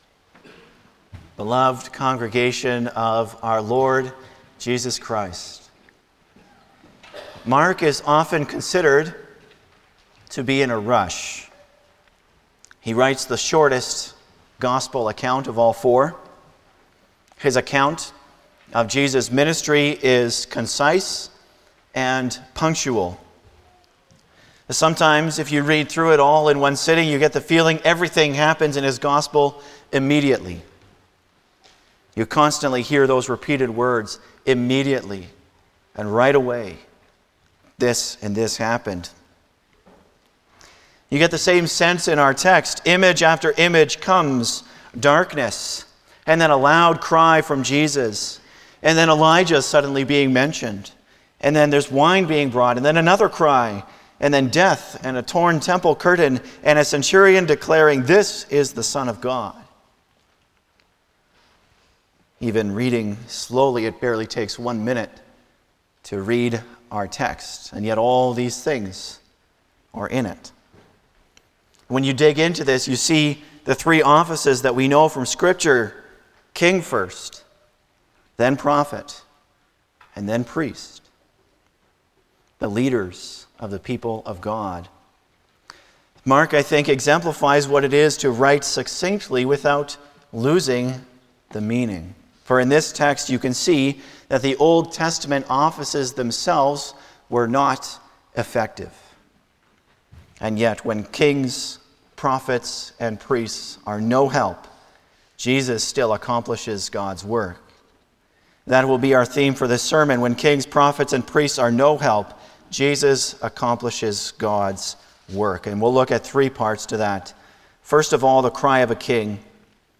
Passage: Mark 15:33-39 Service Type: Sunday morning
09-Sermon.mp3